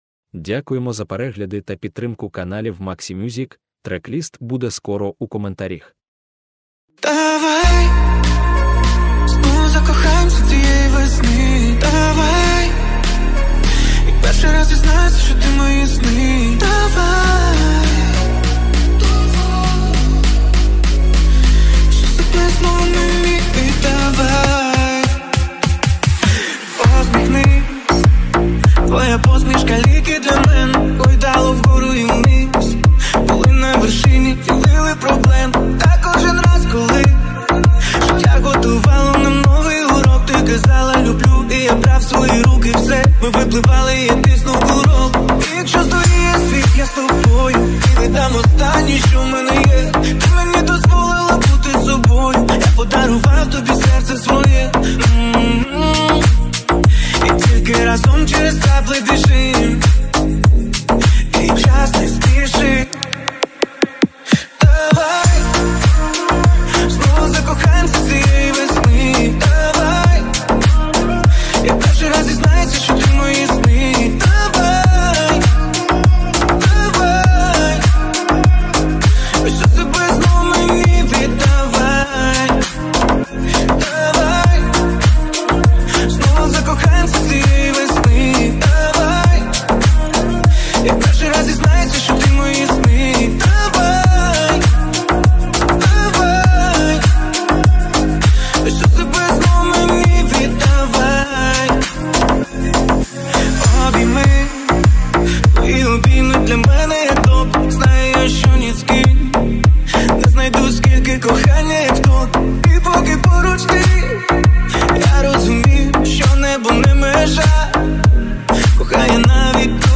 Хіти 2024 Українські Ремікси: